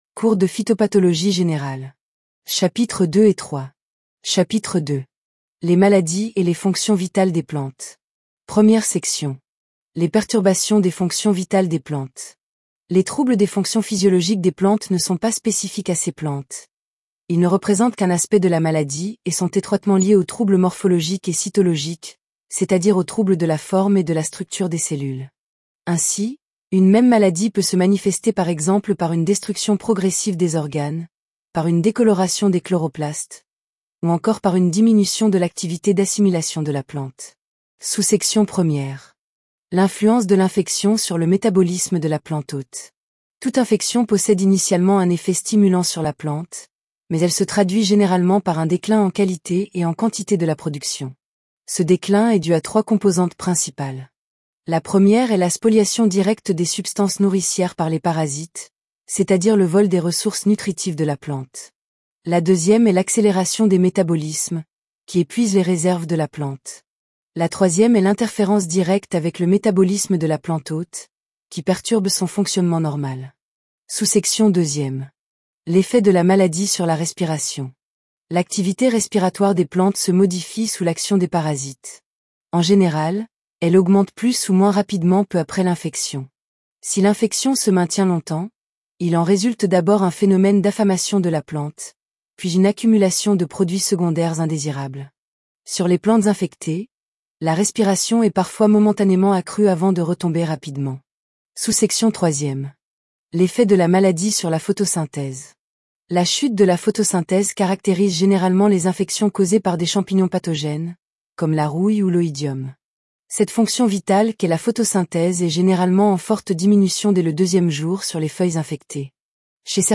Podcast : Phytopath Chap2 Et 3 Tts
Phytopath_Chap2_et_3_TTS.mp3